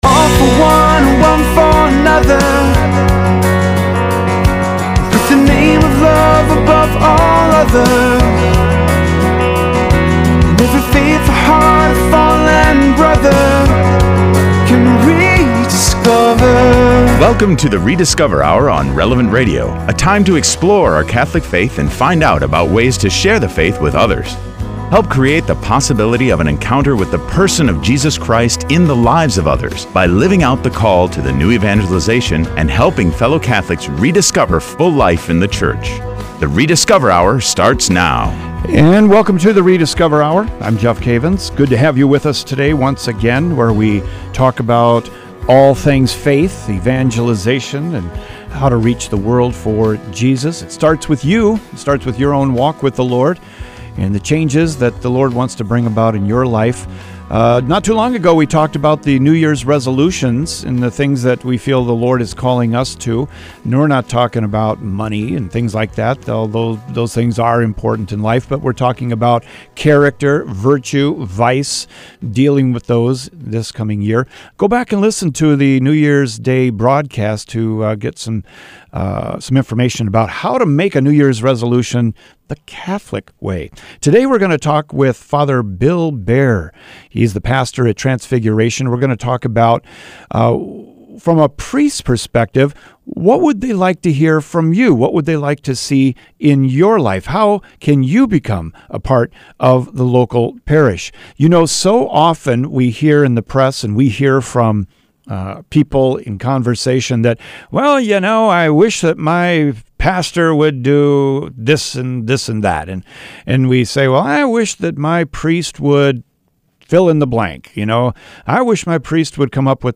On the the Rediscover: Hour, we hear from pastors in our archdiocese about how the laity can work together with them in the New Evangelization.